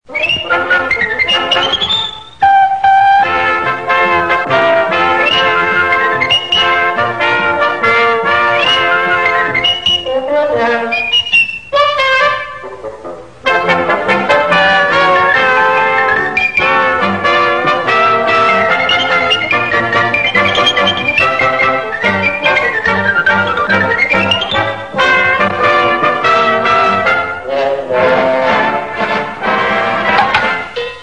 Intro de la serie de dibujos animados